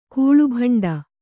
♪ kūḷu bhaṇḍa